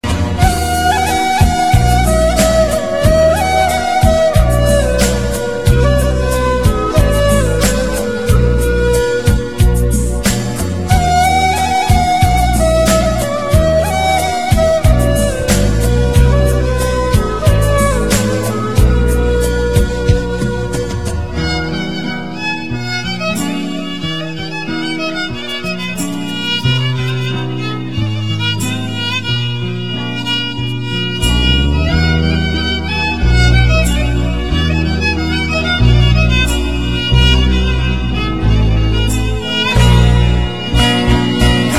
آهنگ زنگ آرام و غمگین
آهنگ غمگین زنگ خور